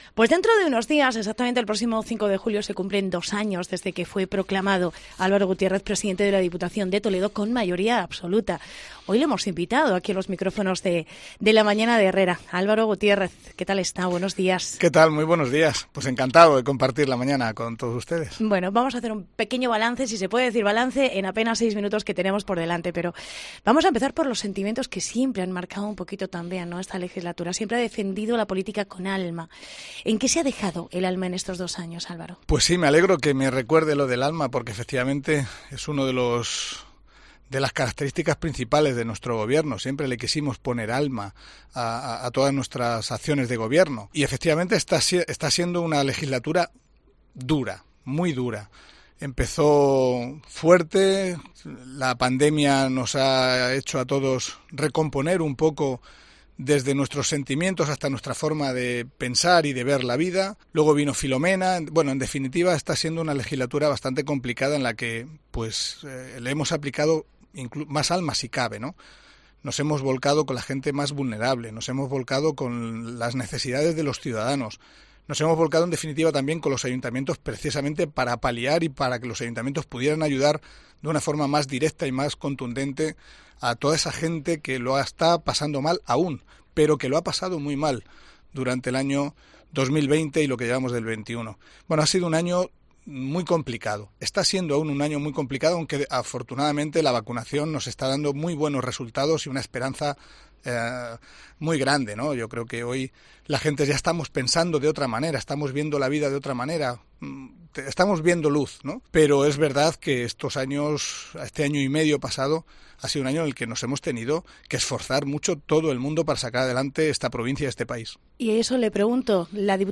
Entrevista con Álvaro Gutiérrez. Pte de la Diputación Provincial de Toledo